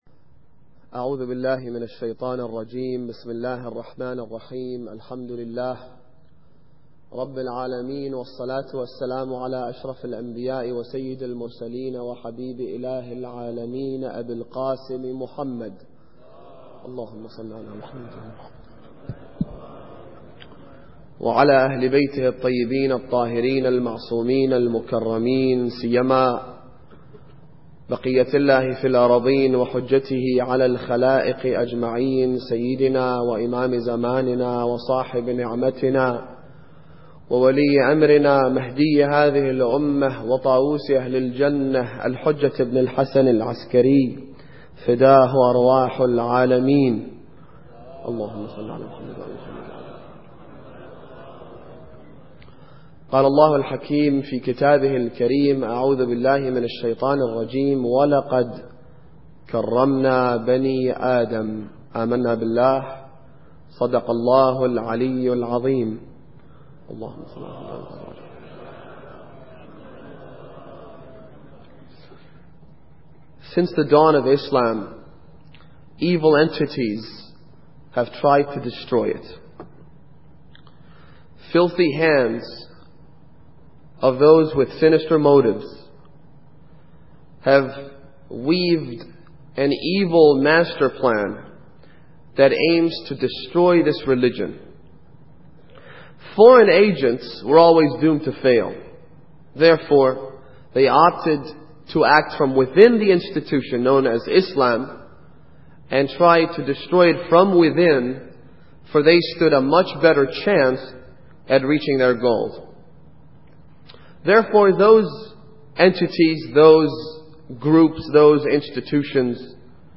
Muharram Lecture 6